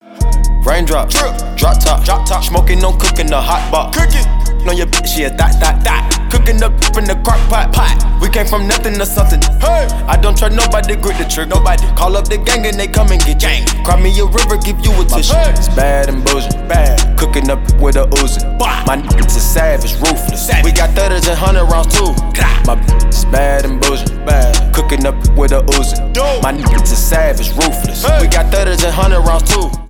• Hip-Hop